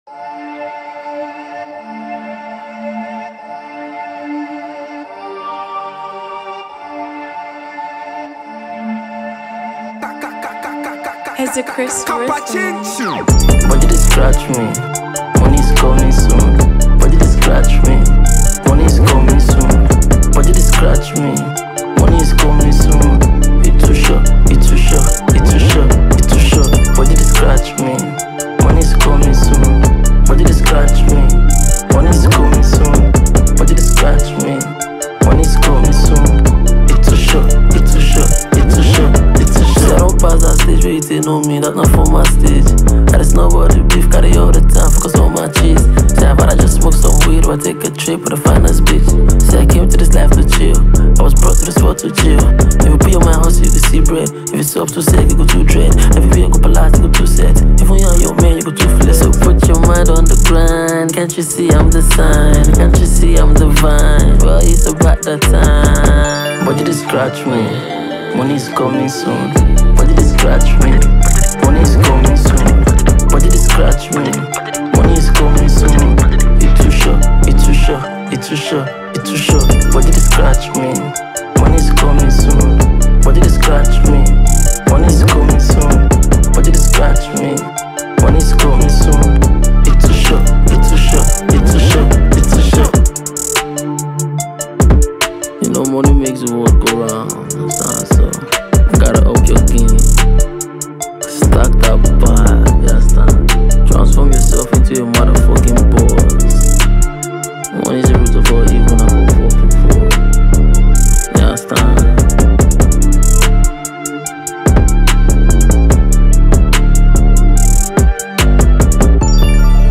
Talented Nigerian rapper and songwriter